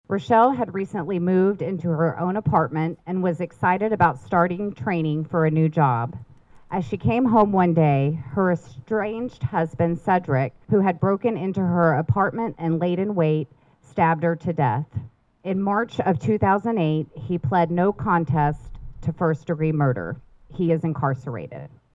A Silent Witness Ceremony was held at the Riley County Courthouse Plaza Wednesday over the lunch hour.
The ceremony included participants reading stories of victims both locally and from around Kansas.